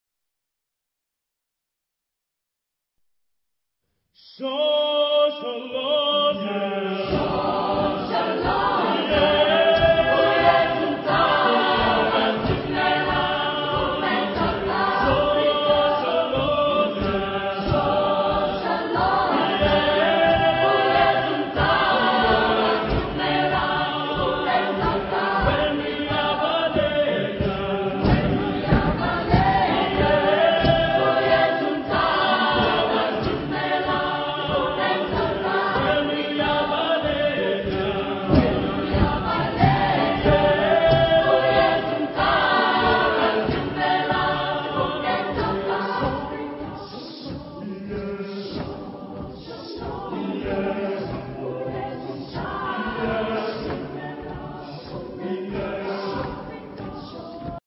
Genre-Style-Forme : Folklore ; Profane
Caractère de la pièce : courageux ; andante
Type de choeur : SATB  (4 voix mixtes )
Tonalité : sol majeur